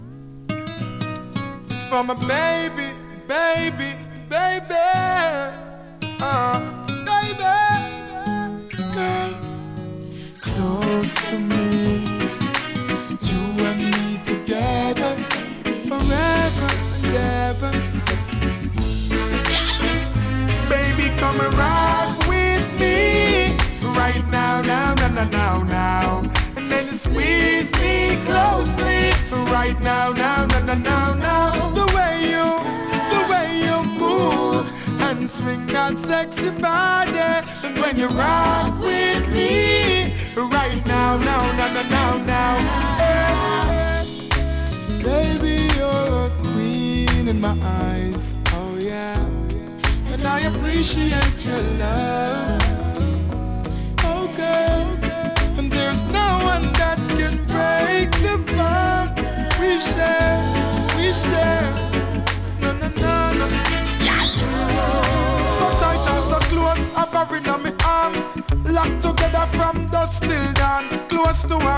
2026 NEW IN!! DANCEHALL!!
スリキズ、ノイズかなり少なめの